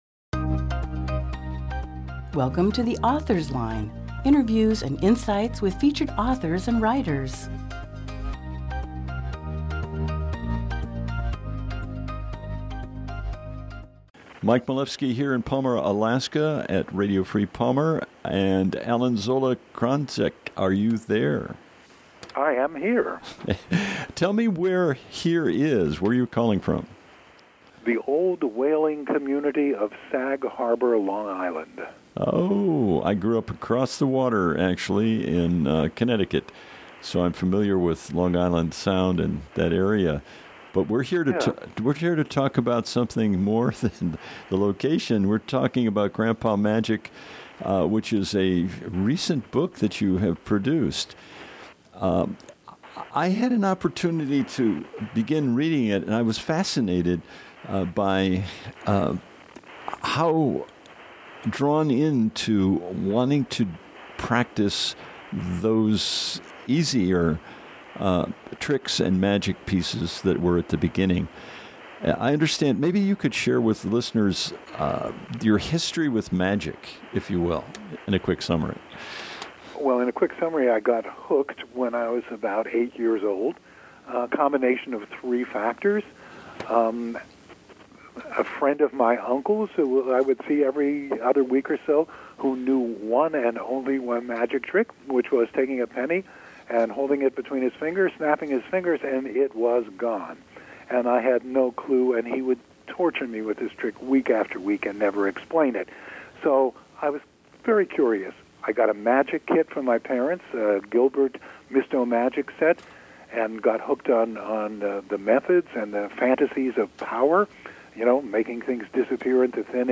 Oct 31, 2018 | Author Interviews